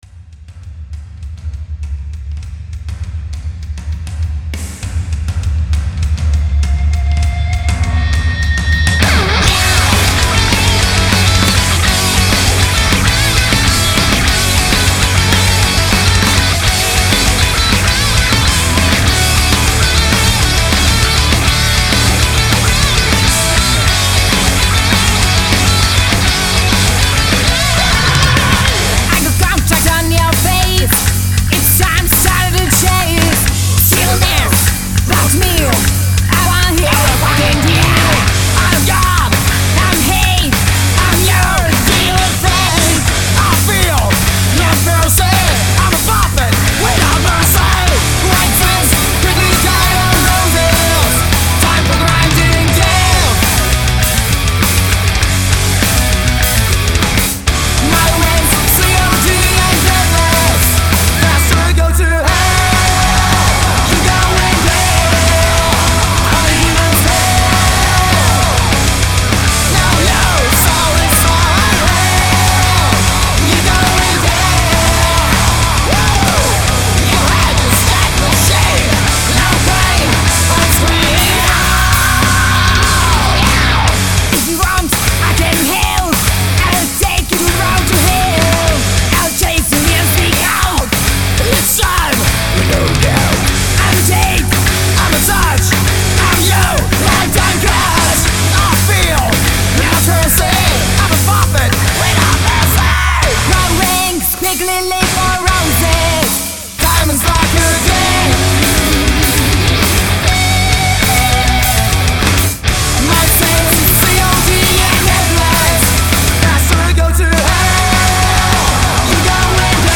Obrovské metalové srdce, /Megadeth boli asi pri nahrávaní/ bábika má krásne "nasratý" spev. Famózna basa.